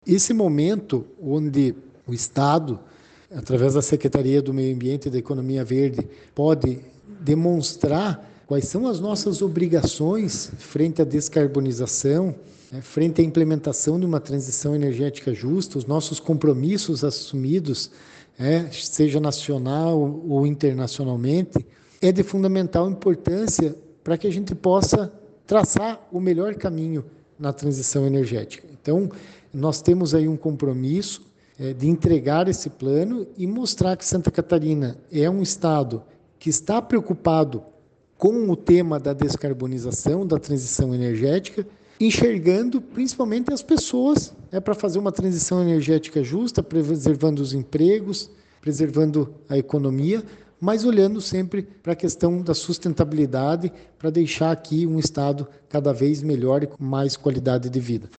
O Governo de Santa Catarina, por meio da Secretaria de Meio Ambiente e Economia Verde (Semae), apresentou os detalhes do processo de Transição Energética, que está sendo elaborado para começar no sul do estado, durante o evento Radar Pocket, promovido pela FIESC, na Associação Comercial e Industrial de Criciúma (Acic).
O secretário de Meio Ambiente e Economia Verde, Guilherme Dallacosta, foi convidado para abrir o evento.
SECOM-Sonora-secretario-de-Meio-Ambiente-e-Economia-Verde-1.mp3